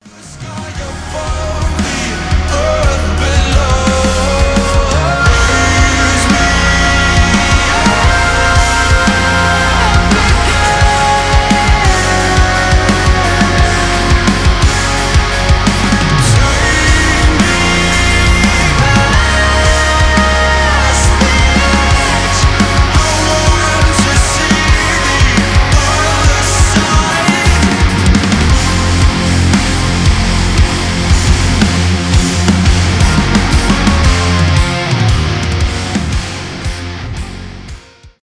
progressive metal , alternative metal
art rock